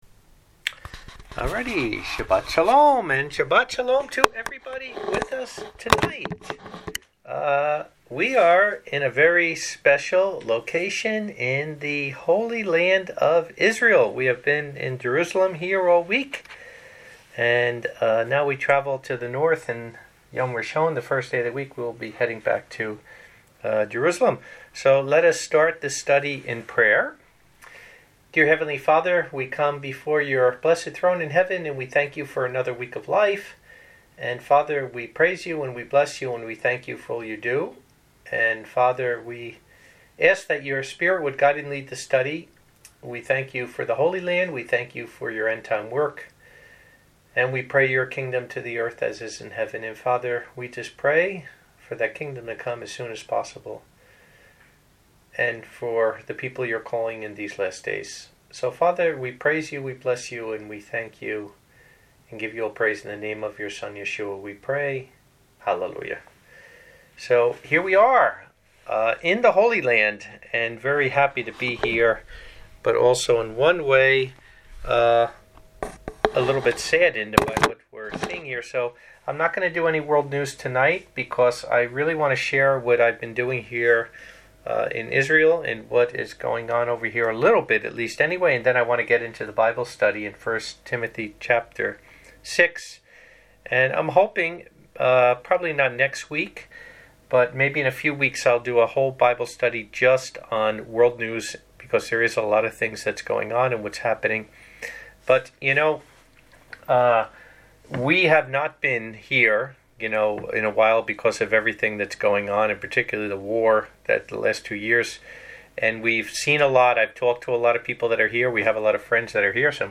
Bible Study_ 1 Tim 6.mp3